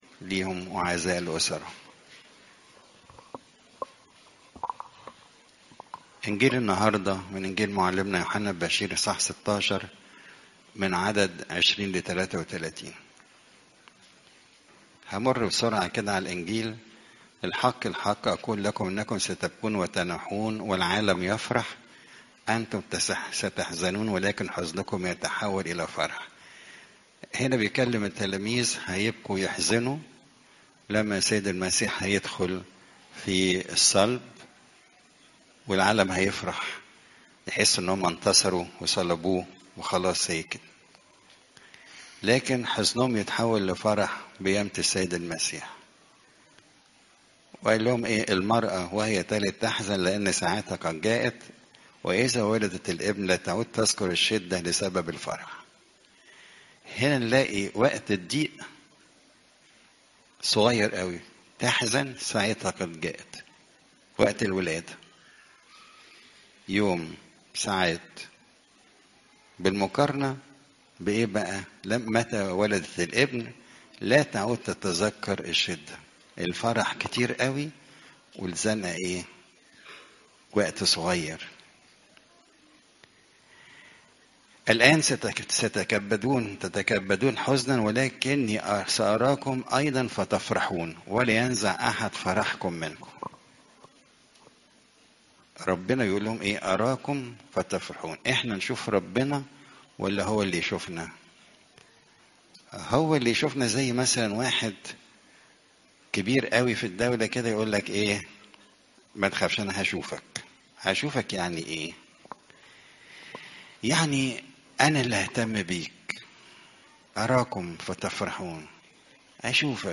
عظات قداسات الكنيسة صوم الميلاد (يو 16 : 20 - 33)